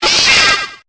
Cri de Berserkatt dans Pokémon Épée et Bouclier.